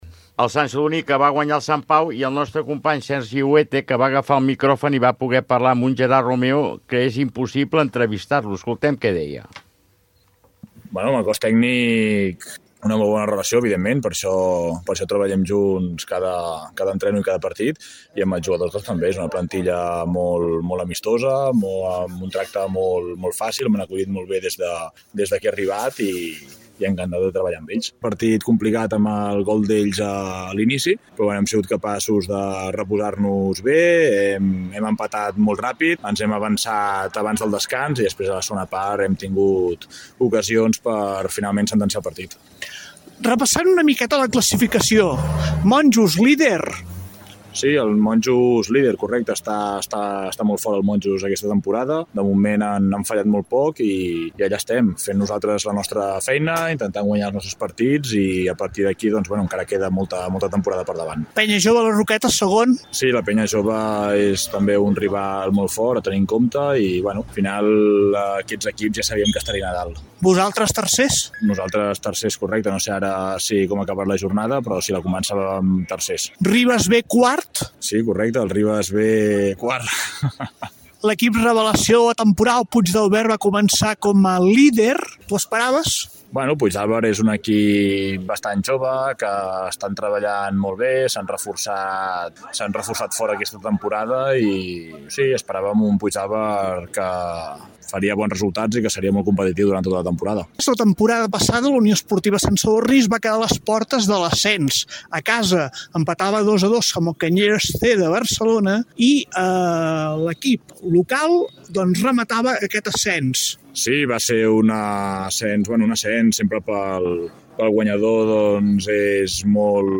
Entrevista
Esportiu